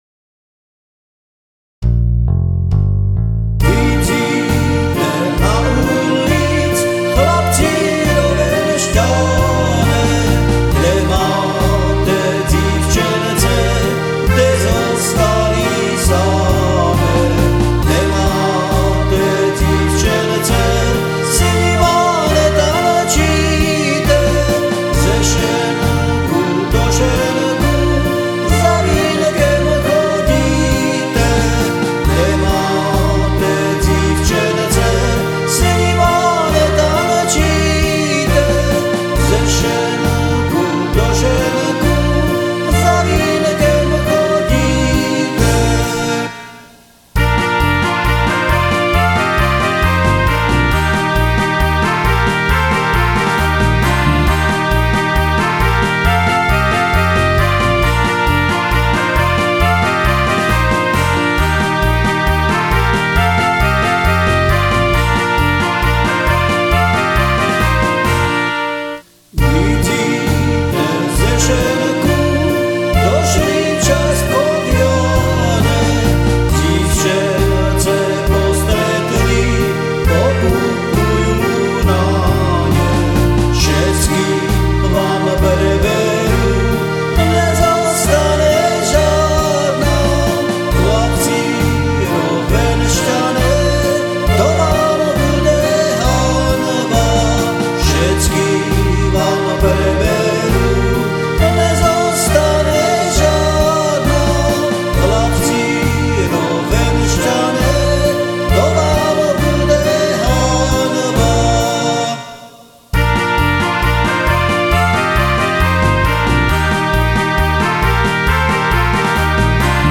Prvý čardáš - snáď sa Častkovjané nenahnevajú !!!